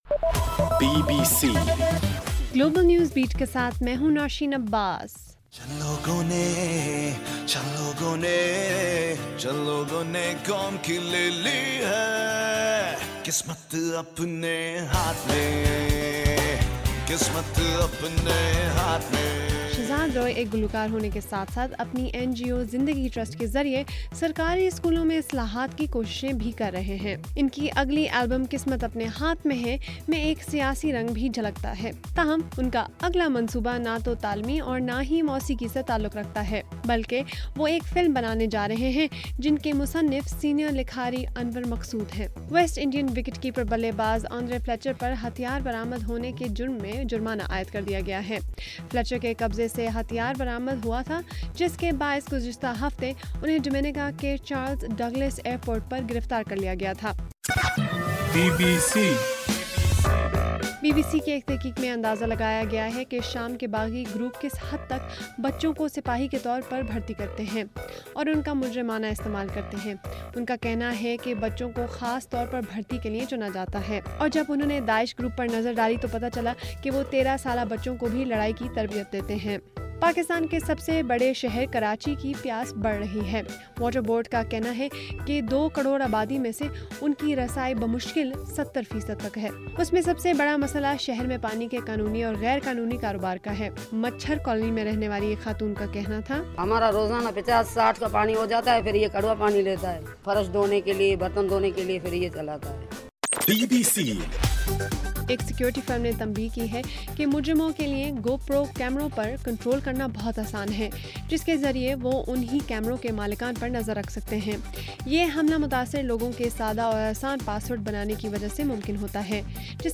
جون 2: صبح1 بجے کا گلوبل نیوز بیٹ بُلیٹن